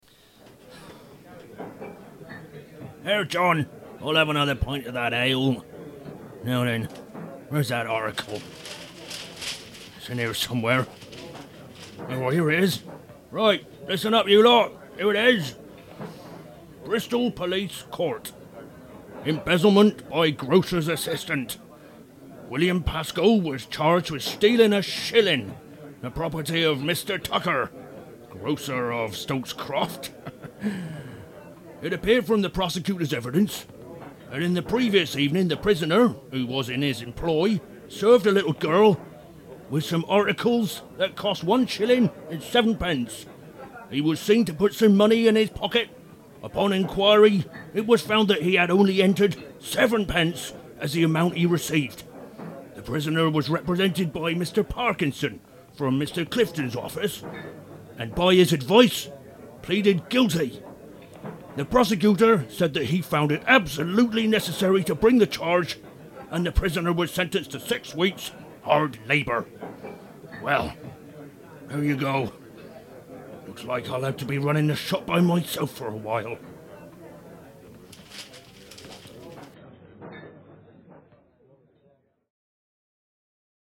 Stokes Croft Radio Play